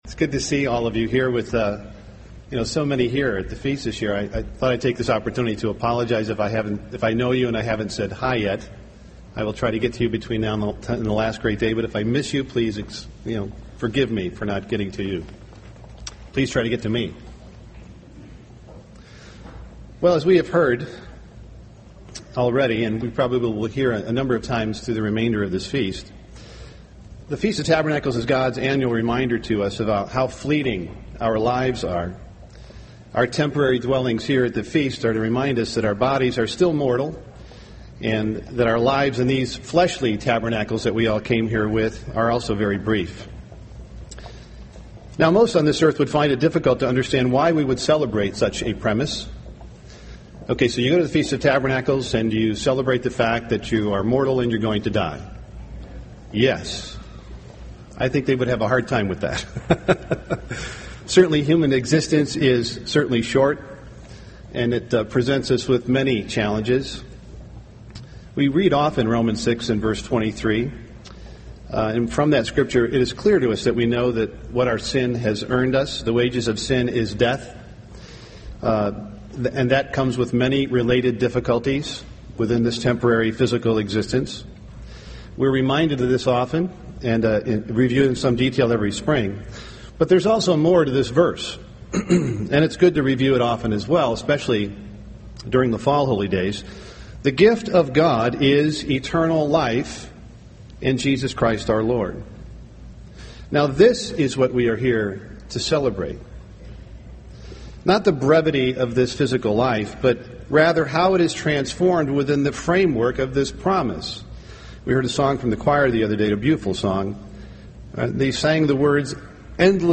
This sermon was given at the Wisconsin Dells, Wisconsin 2010 Feast site.